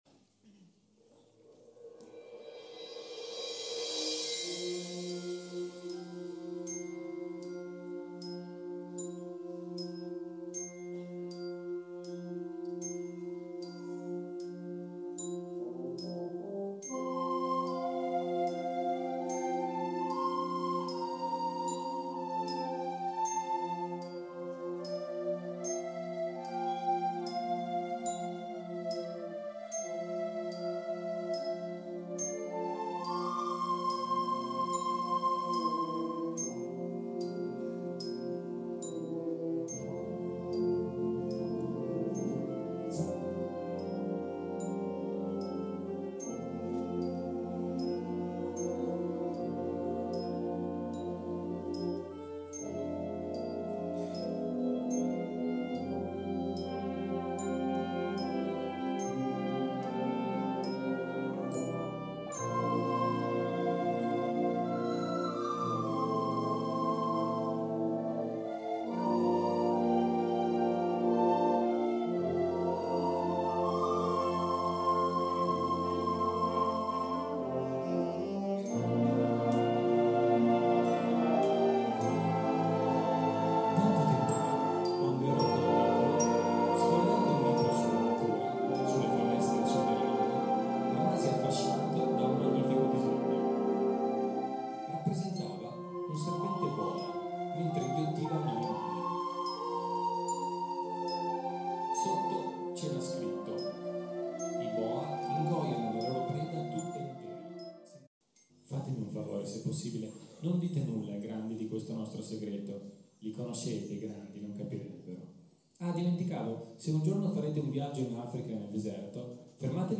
Concerto improntato sulla descrizione grafica degli alunni in diretta con tavoletta grafica e accompagnamento narratore e musica.